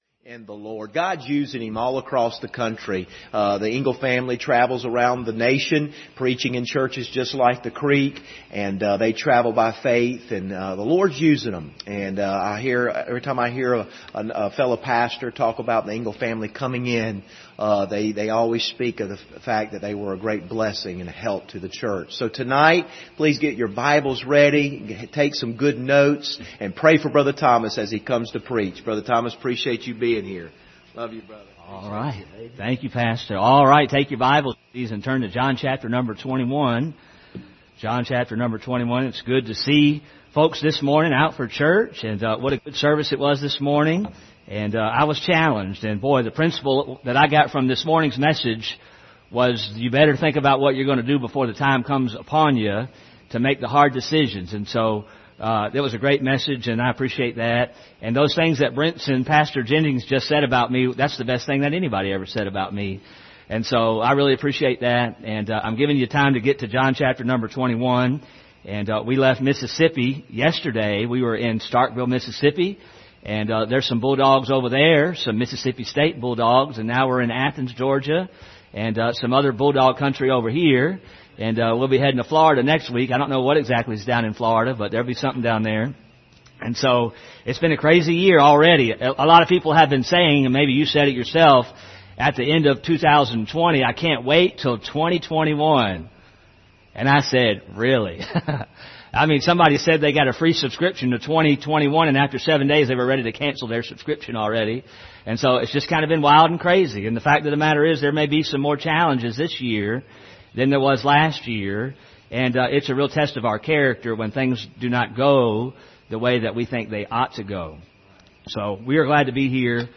John 21:1-3 Service Type: Sunday Evening « Quieting a Noisy Soul Session 8